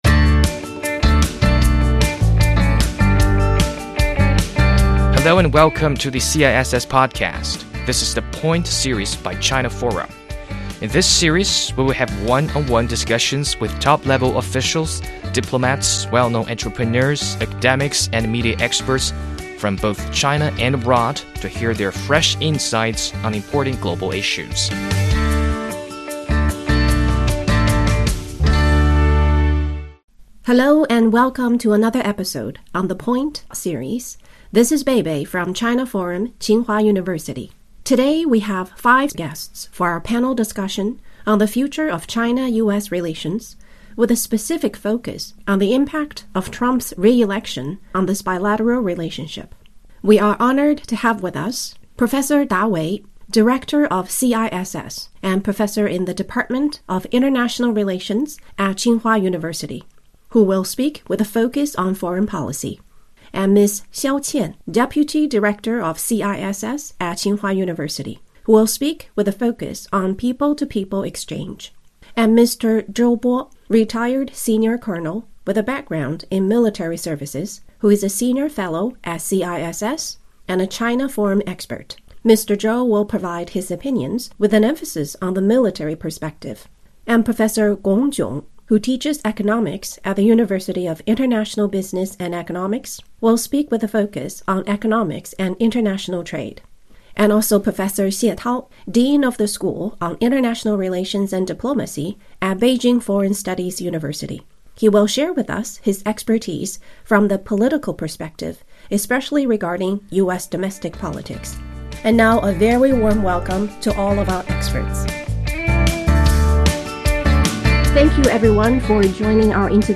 美国当选总统特朗普将于当地时间2025年1月20日，在华盛顿国会山举行自己的第二次就职典礼，美国内外政策或将迎来重大调整。清华大学战略与安全研究中心（CISS）中国论坛特别邀请五位专家围绕特朗普第二任期对中美关系的深远影响展开深入探讨。